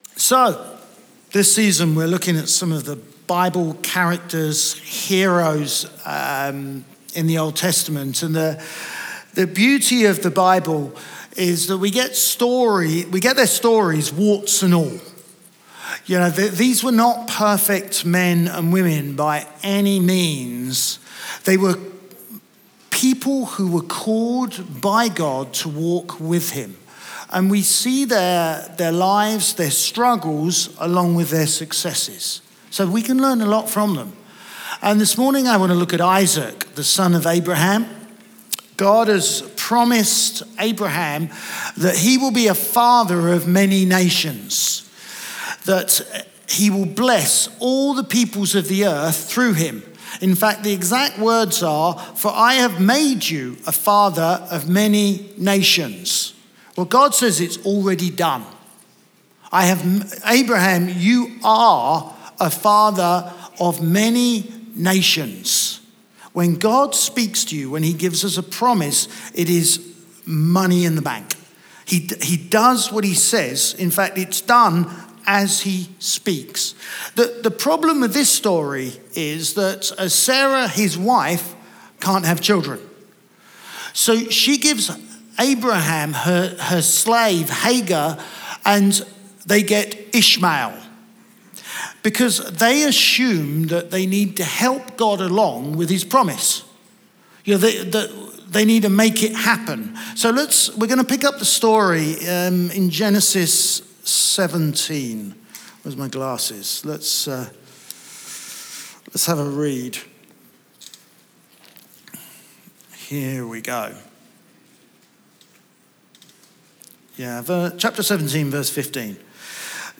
Chroma Church - Sunday Sermon God Always Provides Feb 07 2023 | 00:32:29 1x 00:00 / 00:32:29 Subscribe Share RSS Feed Share Link Embed